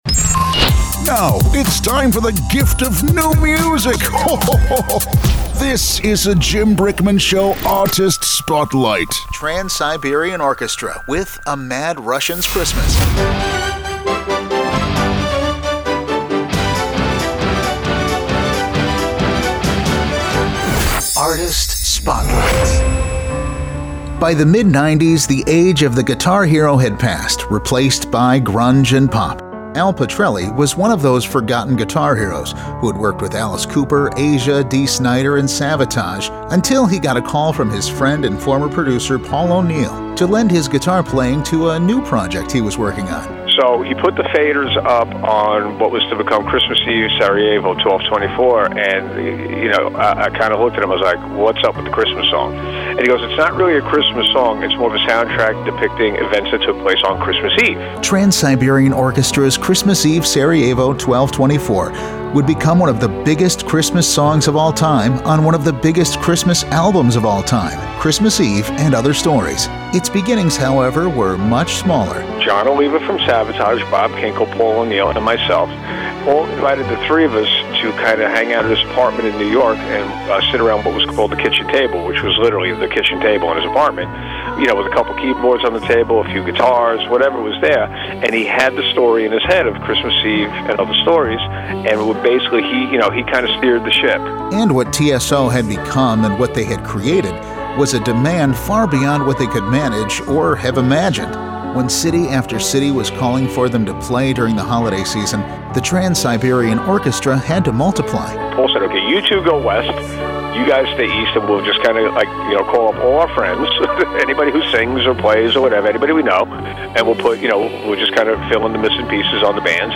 TSO guitarist and musical director, Al Pitrelli talks about how a one-off Christmas song sparked a holiday season phenominon
Artist-Spotlight-TSO-song-attached.mp3